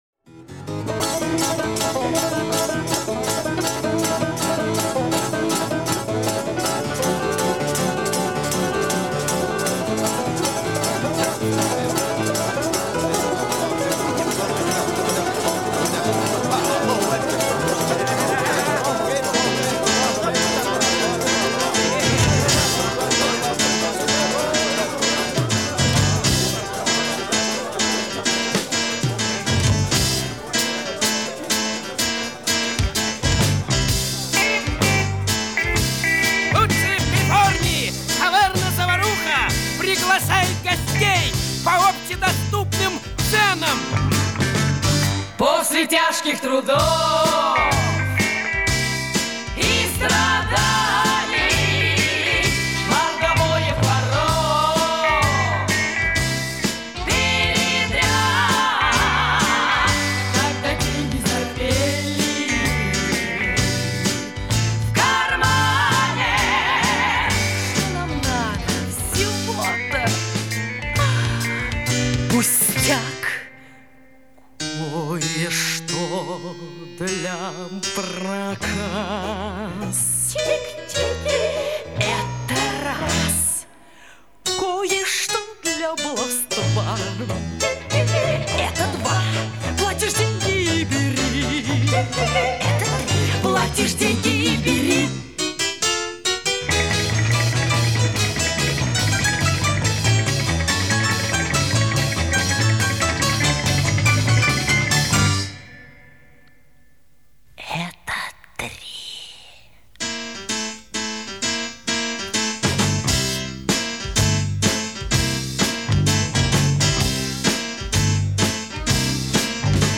Музыка... (песня) Что-то из античности (или эпохи возрождения) В общем ЗДОРОВО!